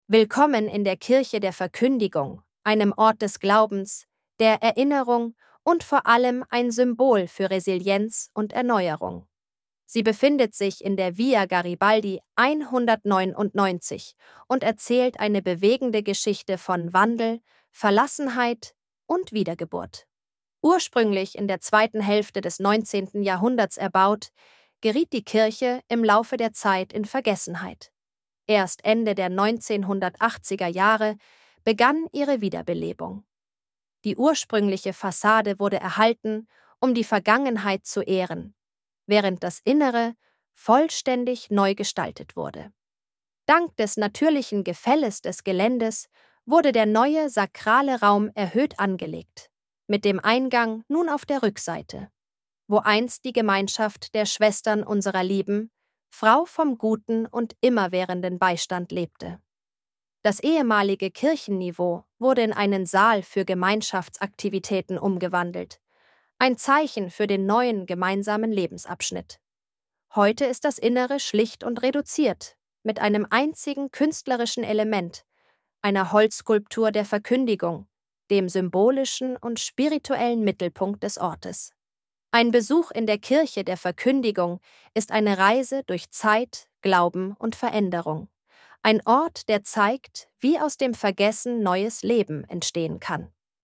Audio Guida